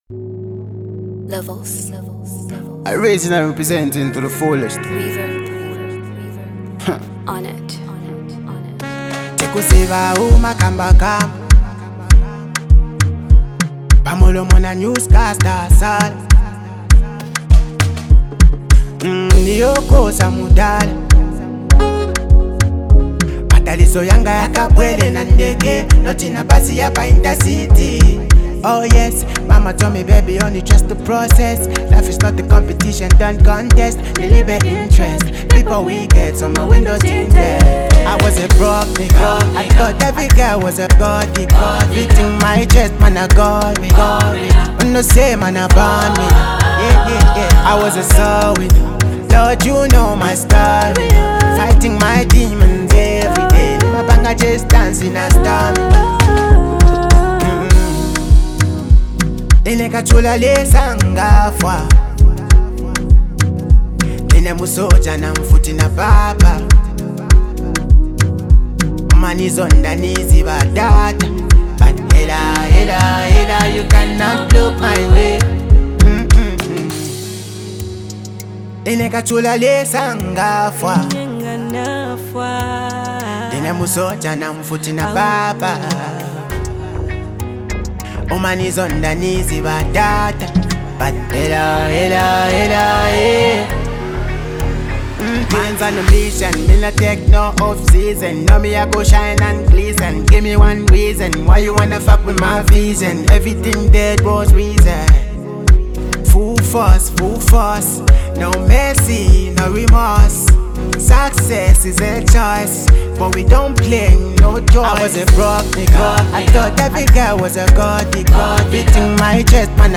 vocal delivery is passionate and sincere